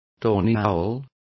Also find out how carabo is pronounced correctly.